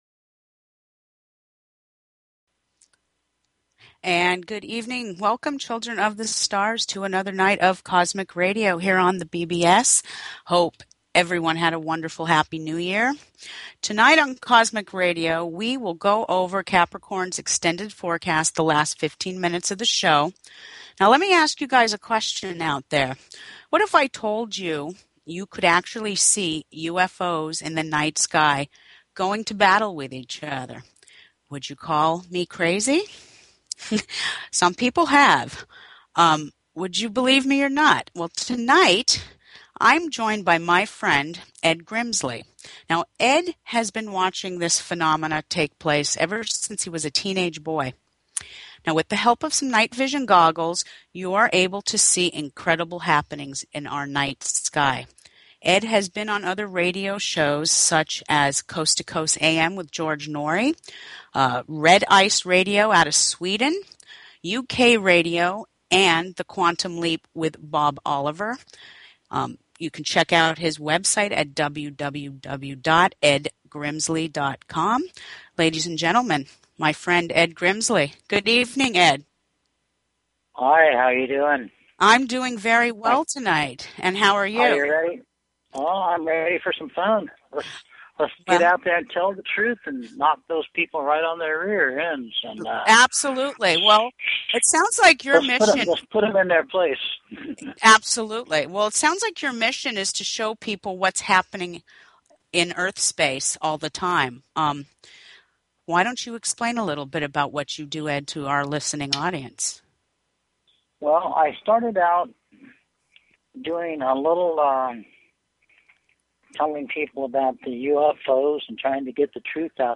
Talk Show Episode, Audio Podcast, Cosmic_Radio and Courtesy of BBS Radio on , show guests , about , categorized as